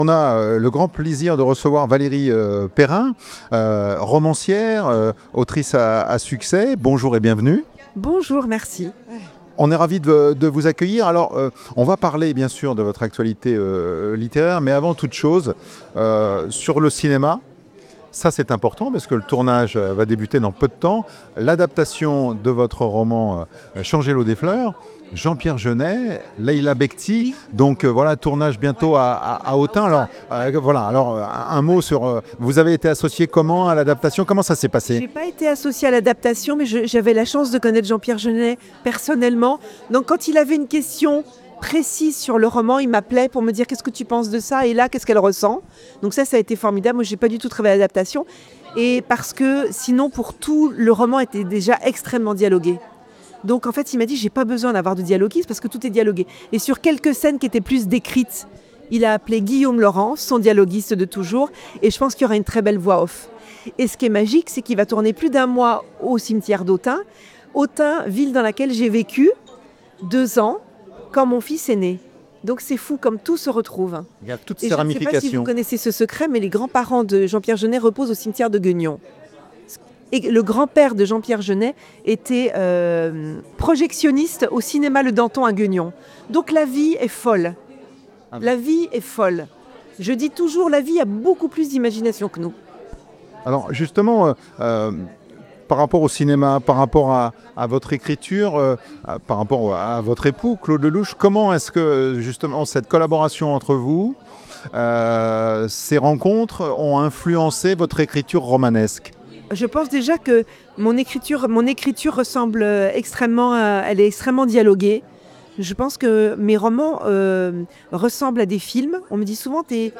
Rencontre au 4ᵉ Salon du Livre de Toulon-sur-Arroux